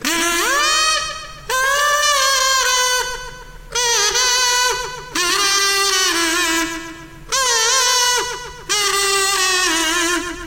Tag: 即兴 免费 卡祖笛 样品 声音